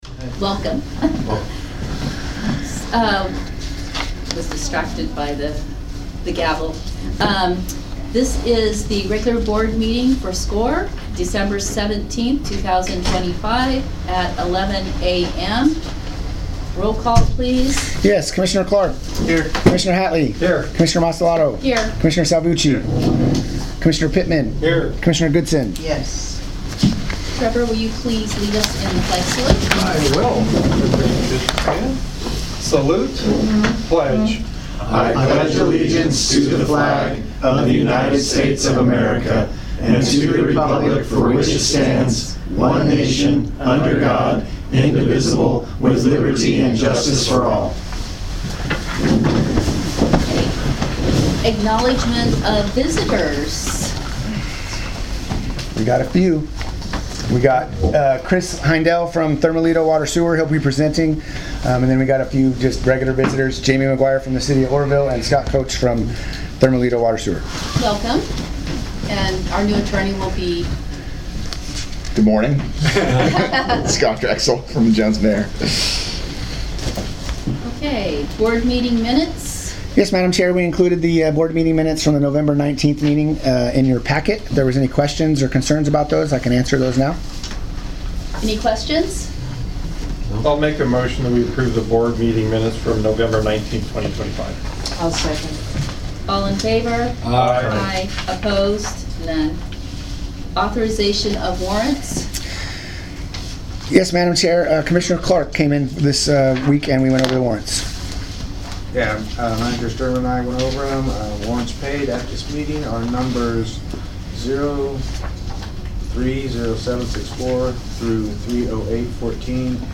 The Sewerage Commission - Oroville Region's Board of Commissioners meets on the fourth Tuesday of each month at 5:00 pm in the board room at its…
Board Meeting